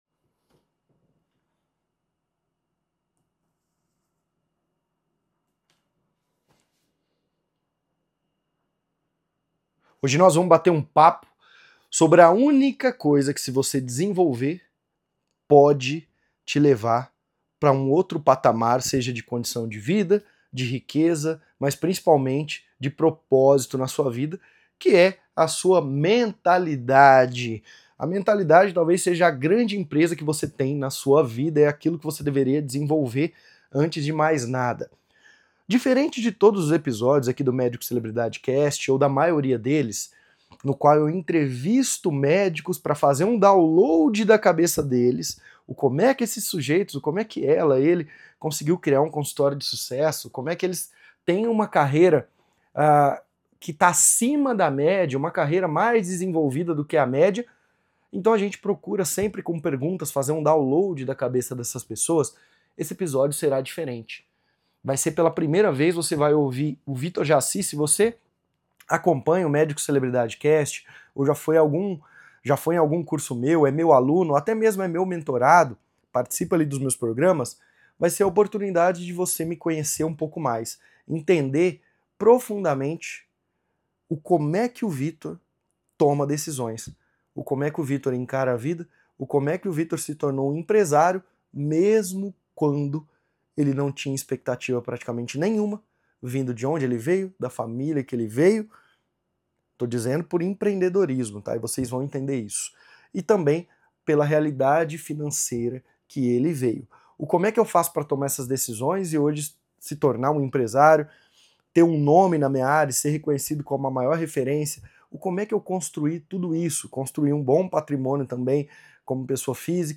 É uma conversa íntima . É uma conversa em que eu abro muitas coisas sobre o meu negócio, sobre a minha vida, sobre a minha mentalidade, sobre os meus sonhos, sobre meus erros e meus acertos.